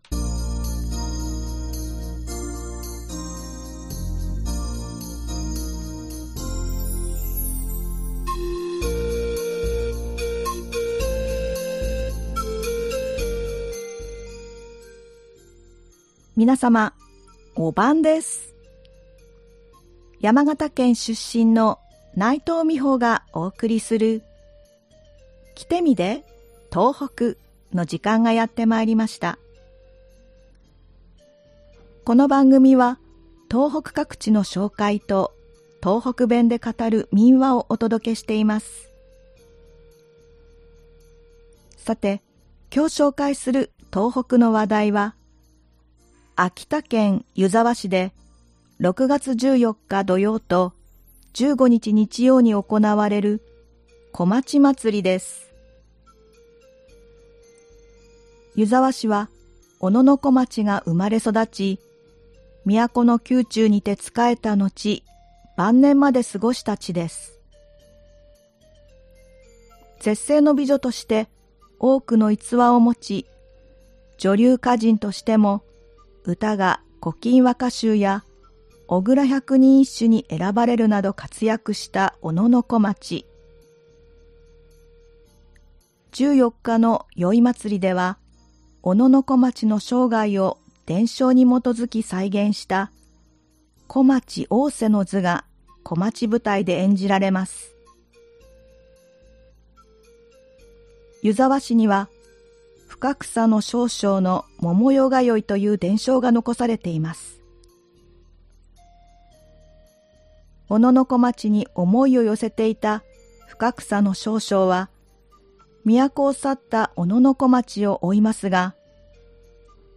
この番組は東北各地の紹介と、東北弁で語る民話をお届けしています。
ではここから、東北弁で語る民話をお送りします。今回は秋田県で語られていた民話「飯かね嫁」です。